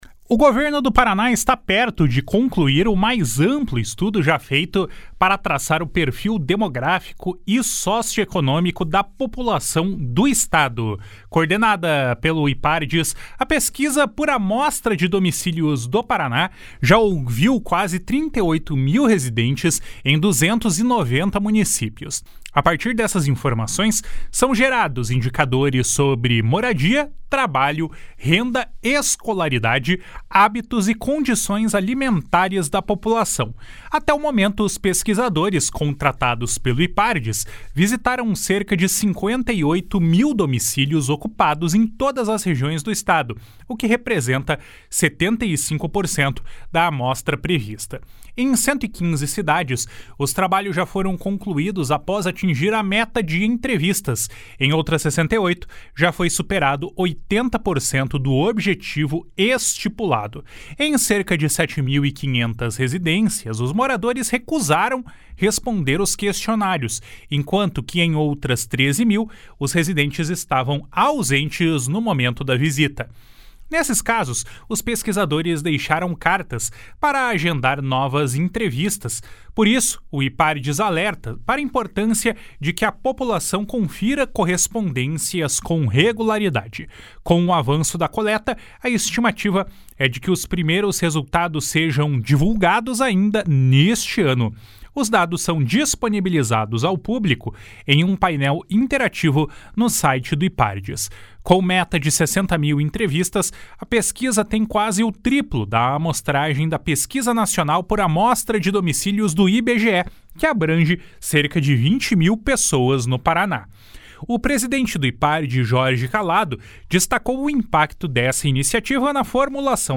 O presidente do Ipardes, Jorge Callado, destacou o impacto dessa iniciativa na formulação de políticas públicas. // SONORA JORGE CALLADO //